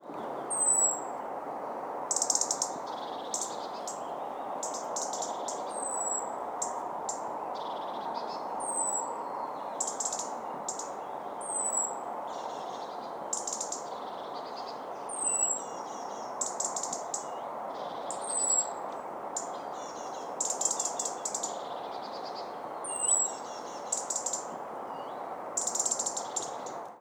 The European Robin’ typical call is a dry “tic”, repeated and uttered in short series, “tic-tic-tic…” as by juveniles as by adults. It also utters a short, tiny, shrill and a plaintive call “siiih” when it is anxious or alarmed.
The song is a series of chirp phrases, soft and clear sounds and of short trills stopping abruptly.
In autumn, its songs are softer, even a little melancholic.
Ang : European Robin
ERITHACUS-RUBECULA-.mp3